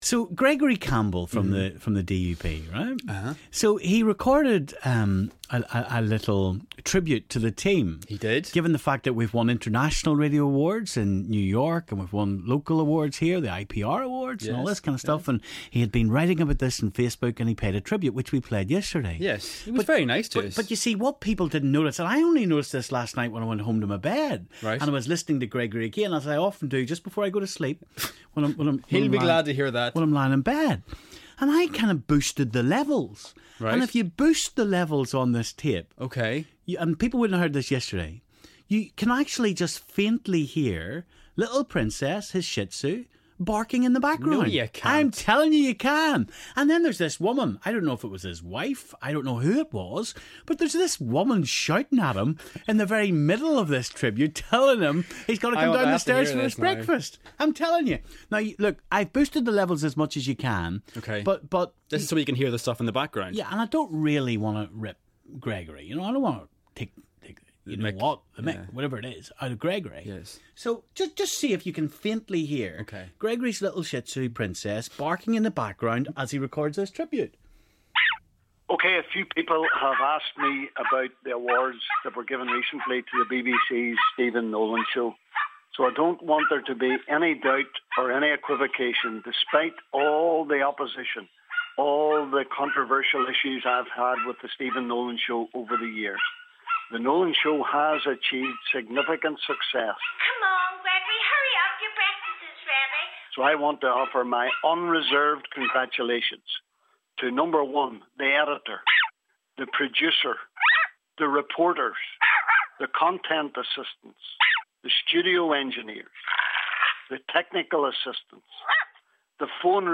DUP MP Gregory Campbell congratulated almost everyone on the Nolan Show for a variety of awards we've won recently. But Stephen thinks he can hear something in the background....